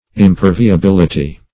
Imperviability \Im*per`vi*a*bil"i*ty\, n. The quality of being imperviable.